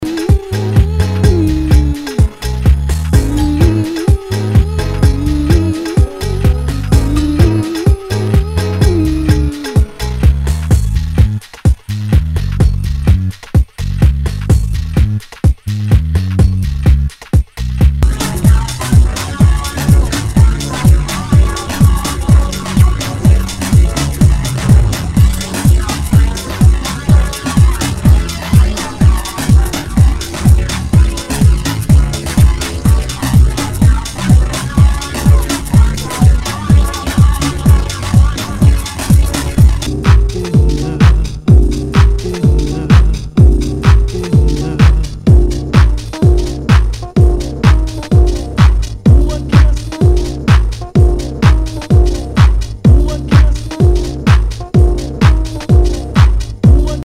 HOUSE/TECHNO/ELECTRO
ナイス！ディープ・ハウス！